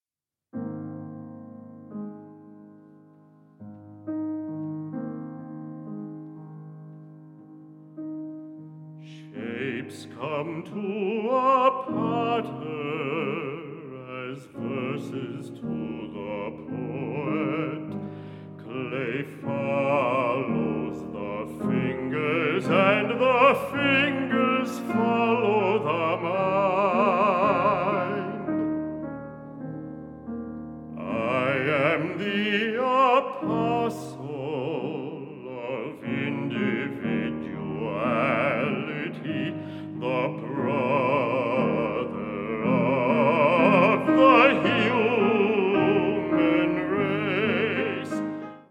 baritone
piano